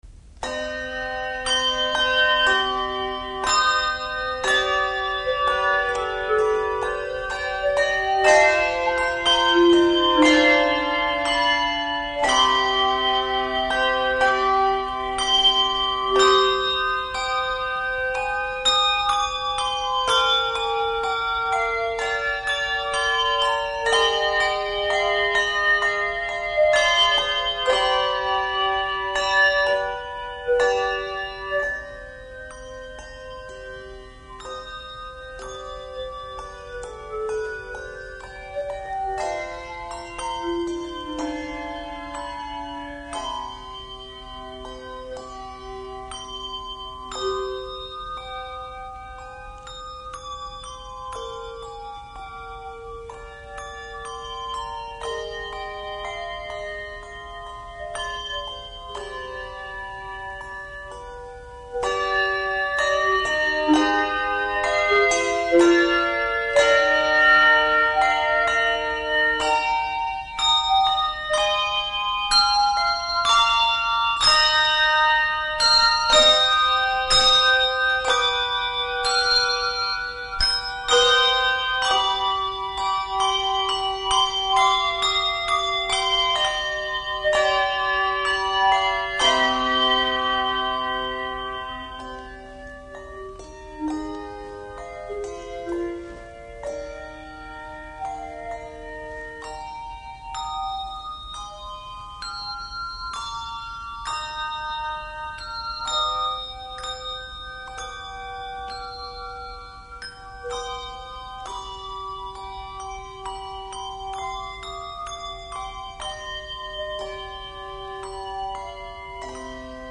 Handbell Quartet